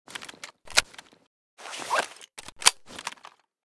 sv98_reload.ogg.bak